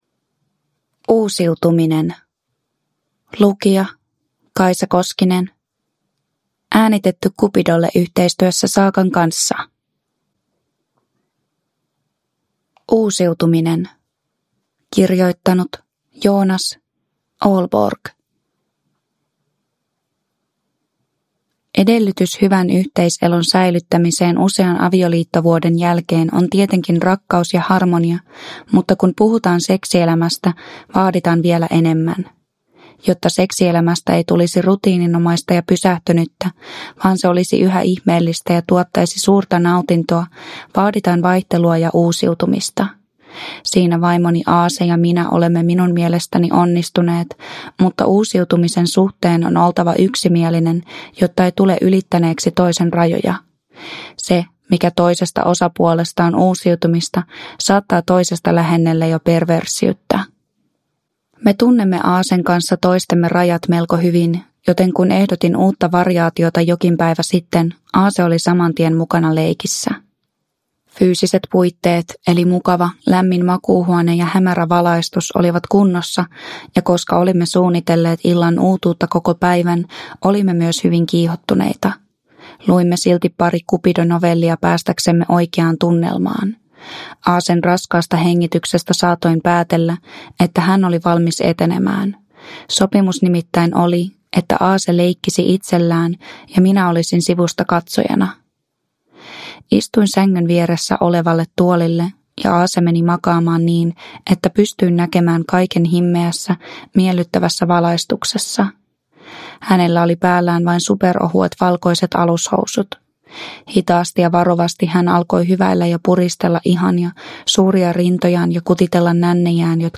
Oppikirja seksiin - ja muita tarinoita Cupidolta (ljudbok) av Cupido